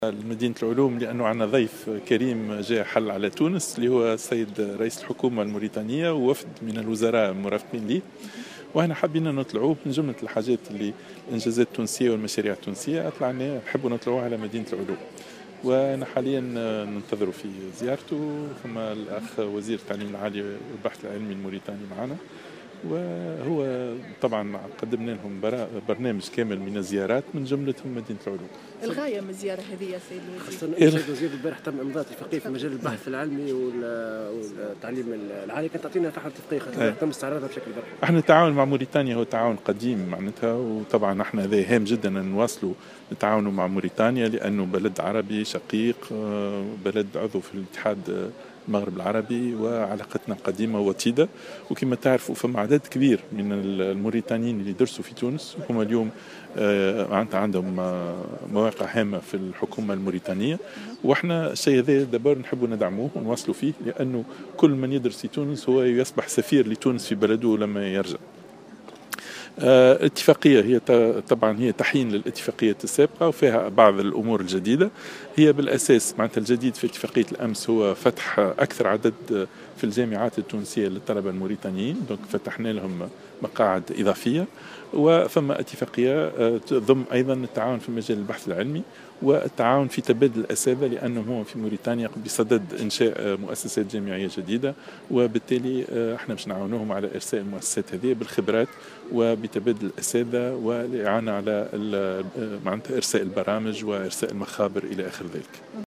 وأوضح بودن في تصريحات صحفية على هامش زيارة إلى مدينة العلوم مع نظيره الموريتاني على شرف رئيس الحكومة الموريتاني الذي يؤدي زيارة رسمية إلى تونس حاليا، أوضح أن هذا المشروع جاء ضمن اتفاق جديد مع موريتانيا سيتم بمقتضاه فتح مجال التعاون بين البلدين من خلال إضافة مقاعد جامعية لفائدة الطلبة الموريتانيين كما سيتم تدعيم جامعات جديدة في موريتانيا بأساتذة من تونس في اطار تبادل الخبرات بين البلدين.